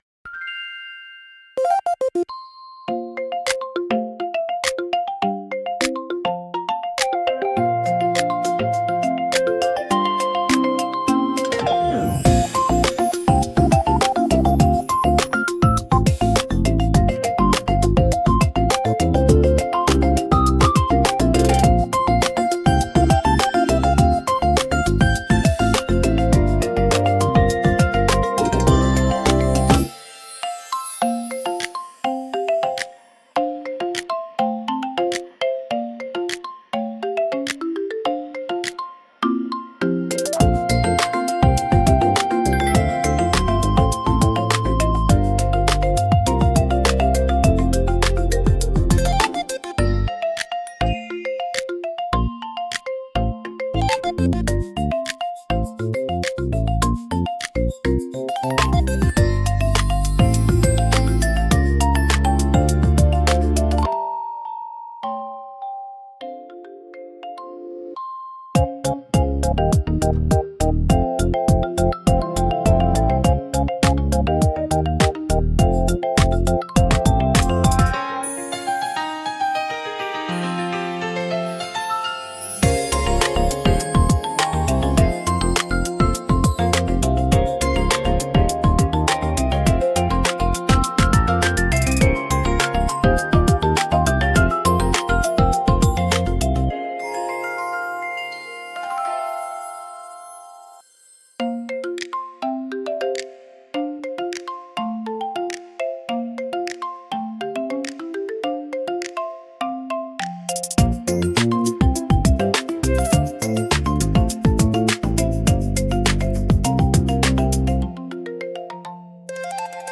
ゆめかわいいフリーBGM🧸🎧🫧
軽やかで可愛いゲーム風BGM